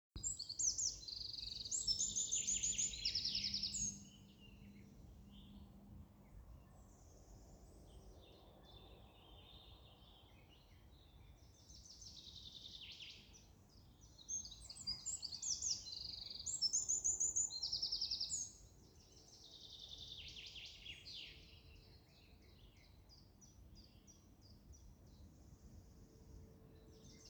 крапивник, Troglodytes troglodytes
Administratīvā teritorijaRīga
СтатусПоёт